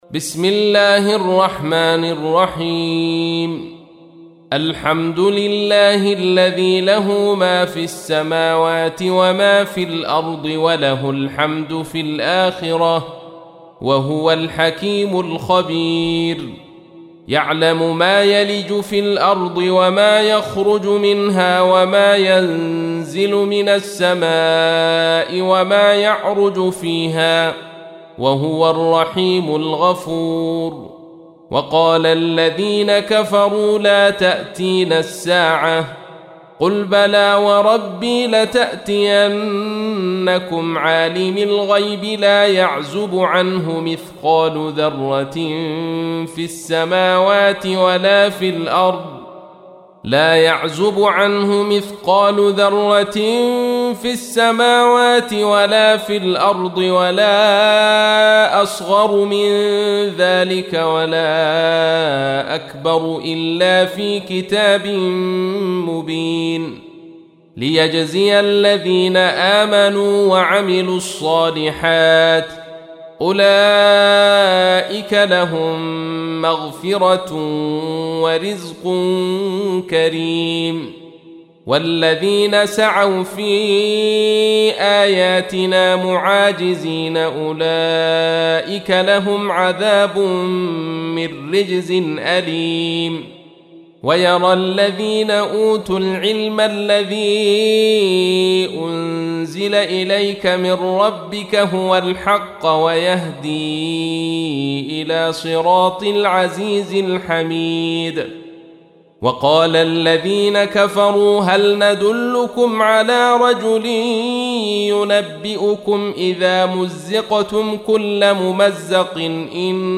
تحميل : 34. سورة سبأ / القارئ عبد الرشيد صوفي / القرآن الكريم / موقع يا حسين